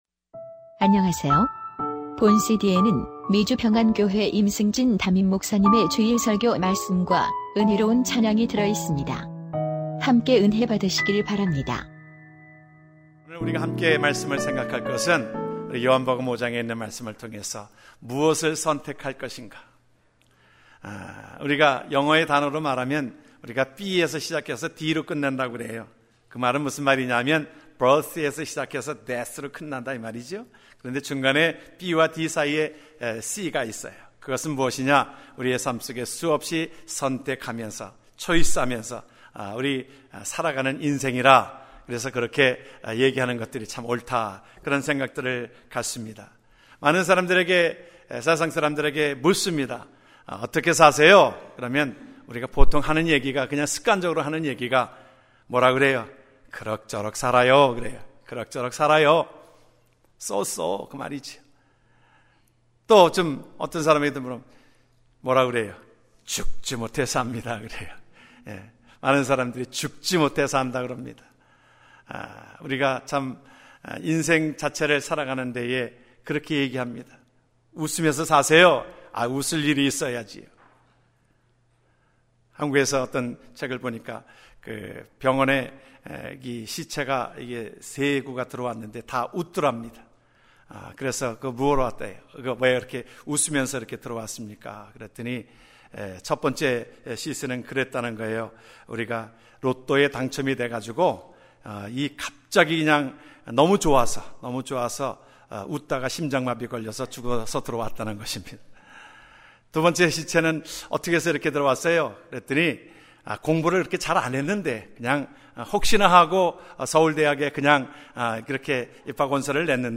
2015년 5월 24일 미주평안교회 주일설교 말씀 무엇을 선택할 것인가?(요5:2-9)